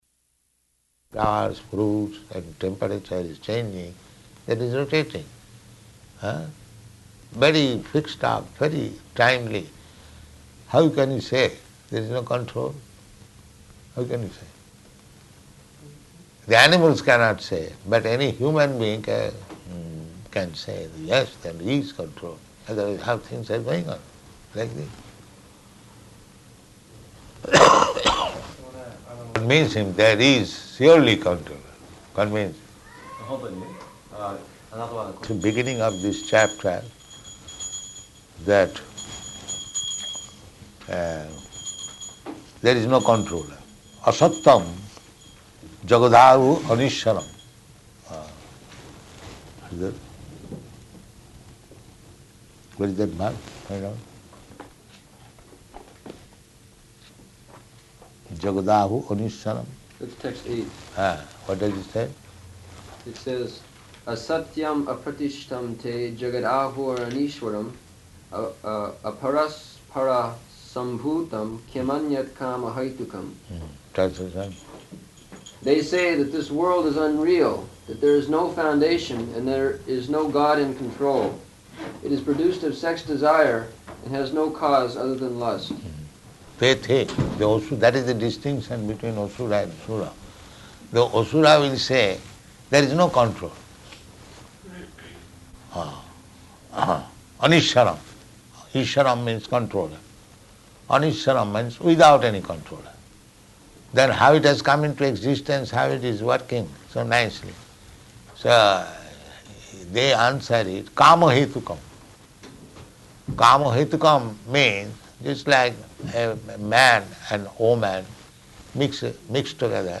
Room Conversation
Room Conversation --:-- --:-- Type: Conversation Dated: January 27th 1975 Location: Tokyo Audio file: 750127R1.TOK.mp3 Prabhupāda: ...flowers, fruits, and temperature is changing.